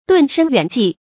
遁身遠跡 注音： ㄉㄨㄣˋ ㄕㄣ ㄧㄨㄢˇ ㄐㄧˋ 讀音讀法： 意思解釋： 猶言避世隱居。